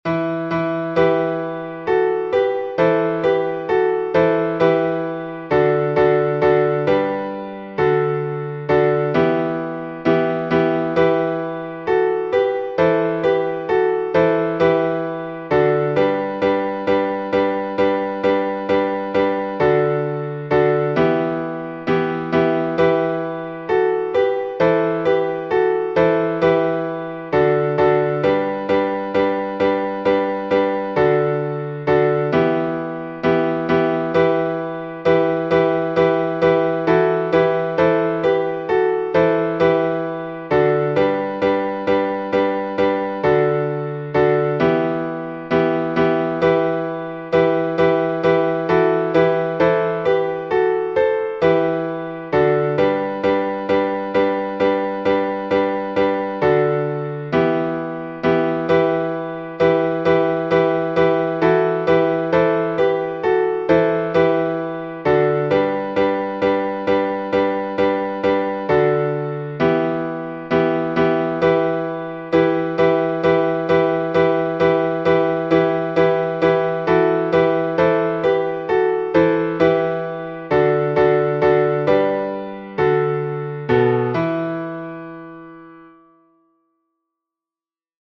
ancient_chant.mp3